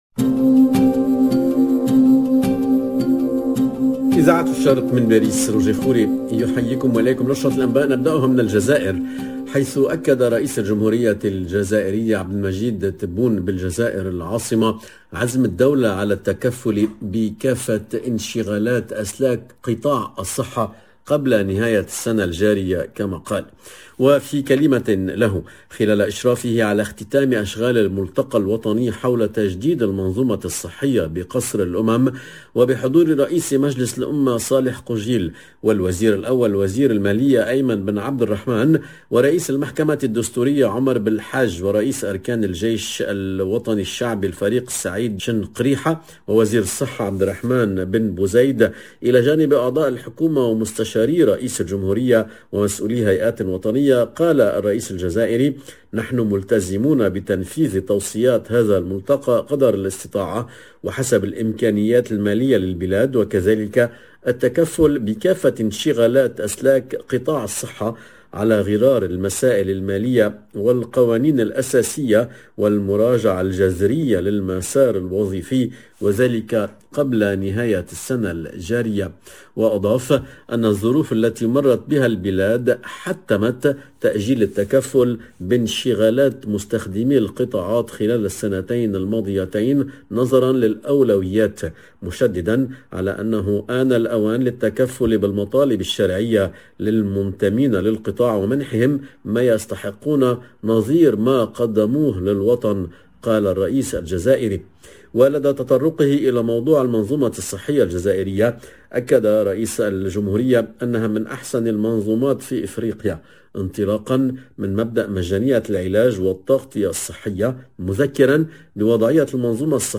LE JOURNAL DE MIDI 30 EN LANGUE ARABE DU 10/01/22